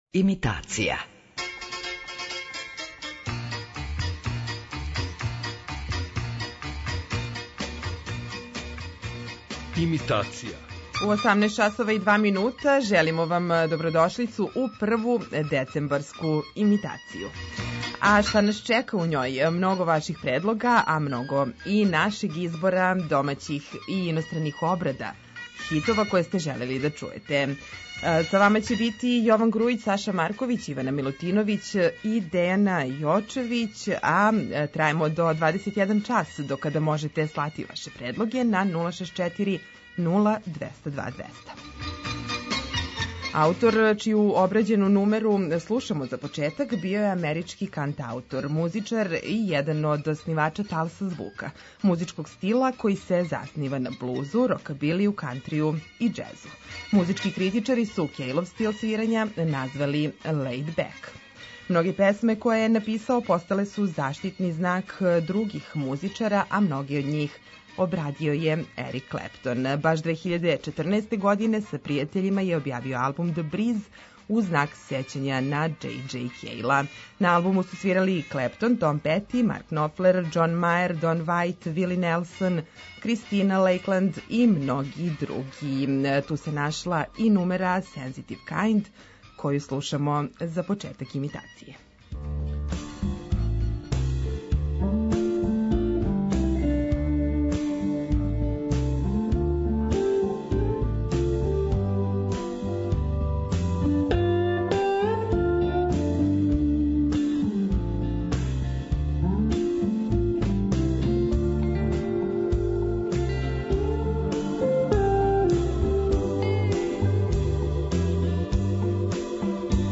Имитација је емисија у којој емитујeмо обраде познатих хитова домаће и иностране музике.